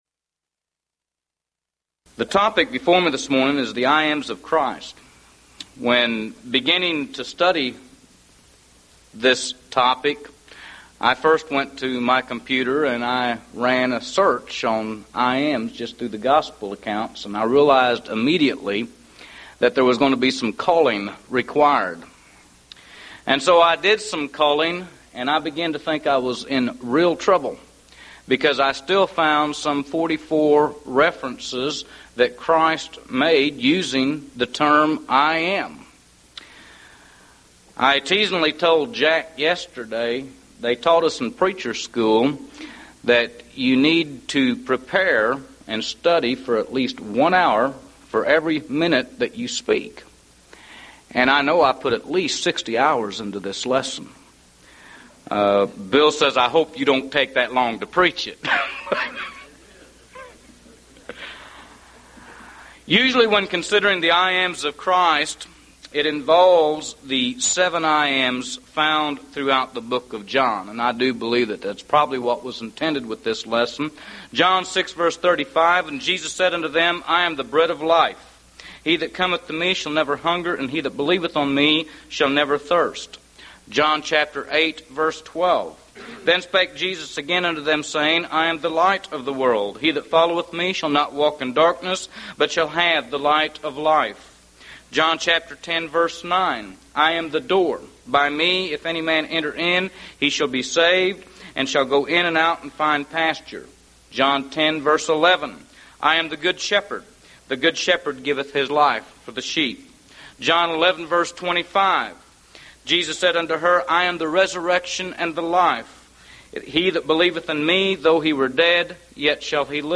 Event: 1998 Mid-West Lectures
this lecture